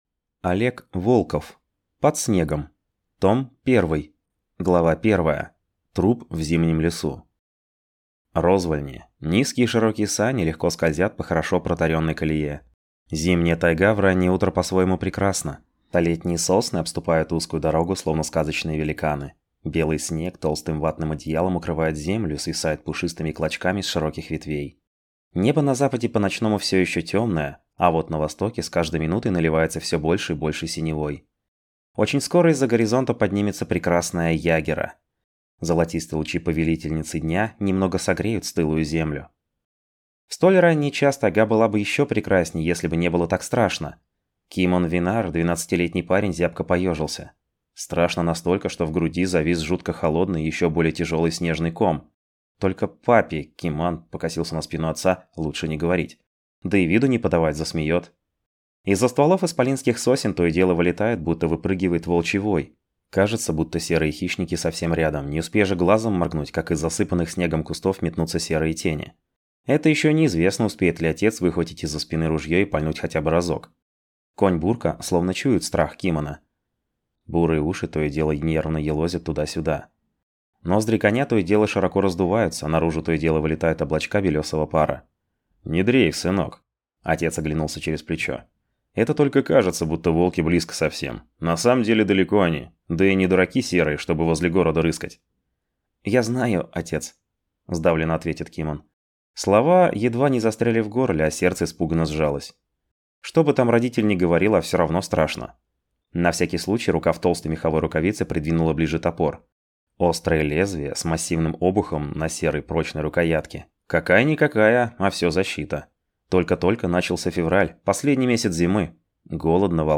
Аудиокнига Под снегом. Том I | Библиотека аудиокниг